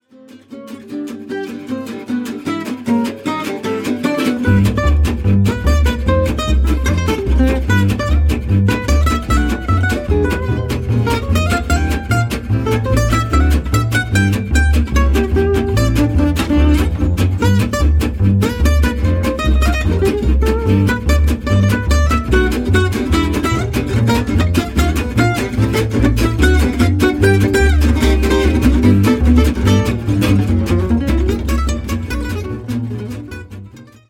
jazz manouche